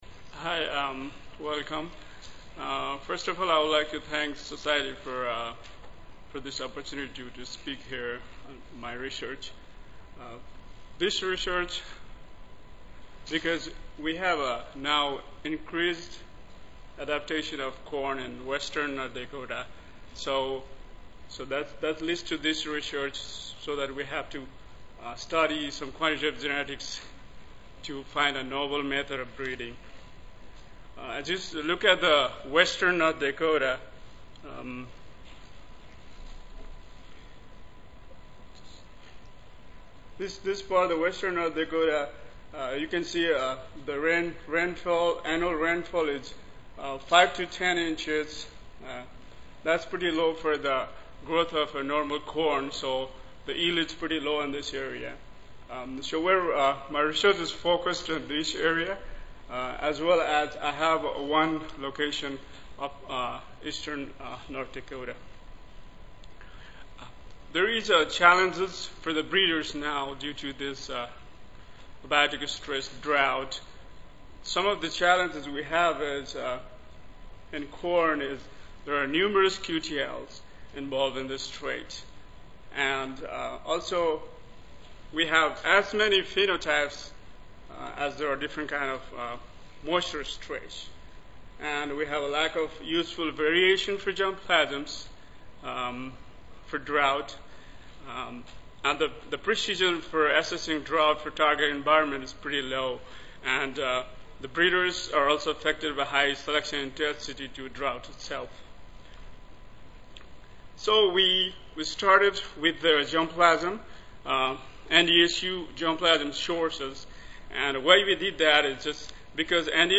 North Dakota State University Audio File Recorded Presentation